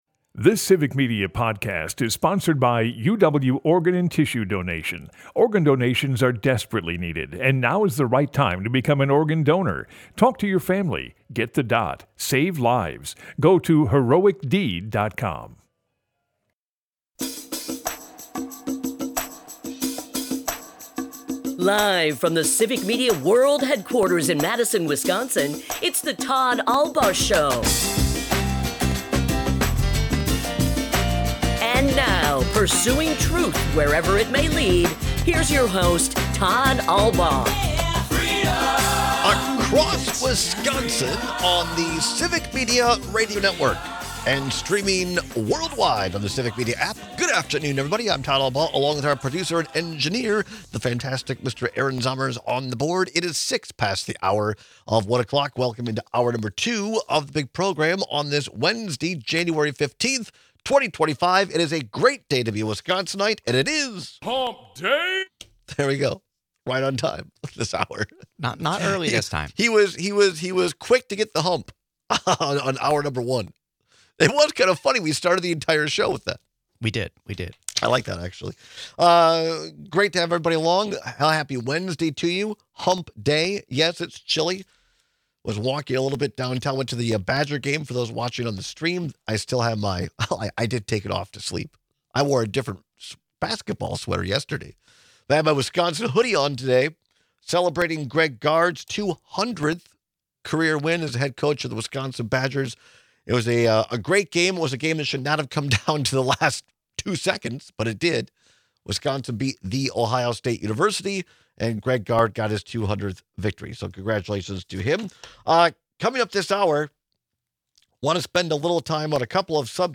These two might seem entirely unrelated, but they’re both things people tend to have strong feelings about. We take a lot of calls and texts with your opinions!
Yesterday, we heard some clips from Pete Hegseth’s confirmation hearing, and today we listen in on Pam Bondi’s Attorney General hearing.